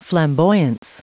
Prononciation du mot flamboyance en anglais (fichier audio)
Prononciation du mot : flamboyance